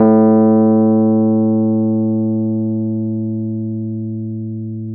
RHODES-A1.wav